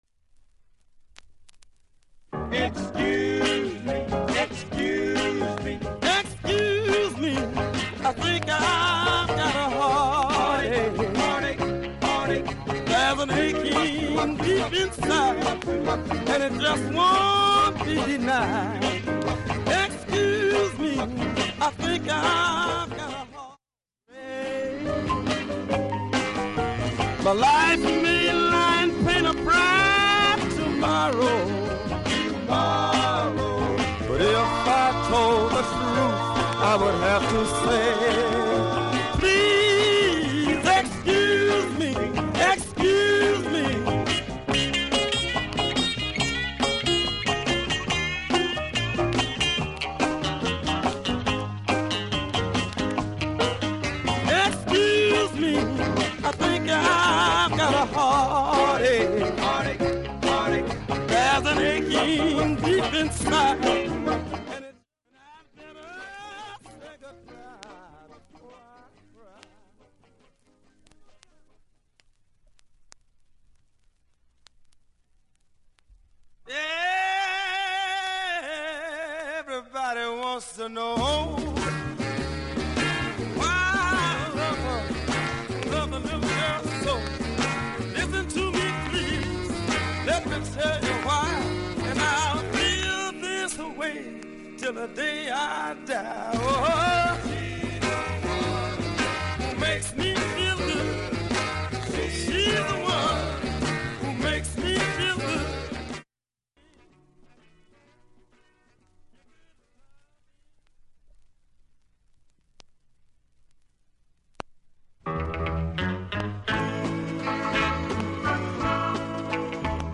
それだけキズが無く音質クリアーだと思います。 現物の試聴（上記）できます。
その他音質良好です。